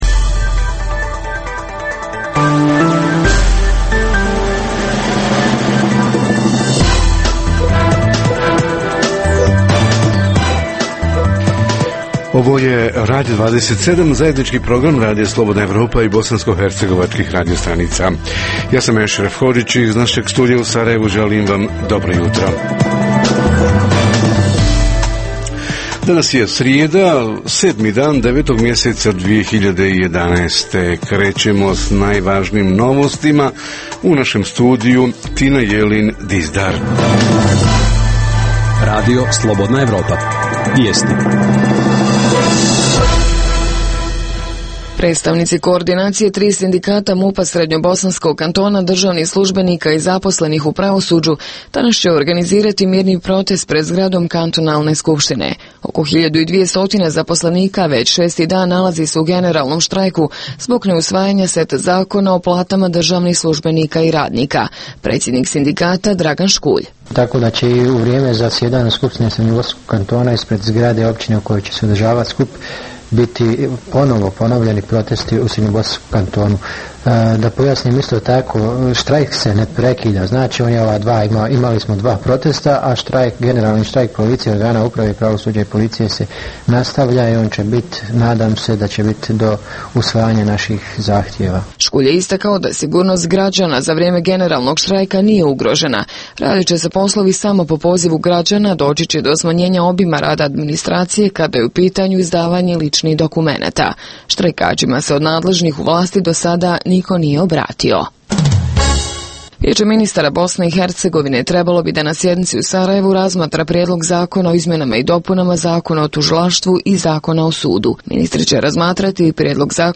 Tema Radija 27: Centri za socijalni rad – kako žive socijalno ugroženi, oni što primaju novčanu pomoć ili naknadu za tuđu njegu? Reporteri iz cijele BiH javljaju o najaktuelnijim događajima u njihovim sredinama.